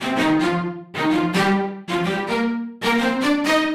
Index of /musicradar/uk-garage-samples/128bpm Lines n Loops/Synths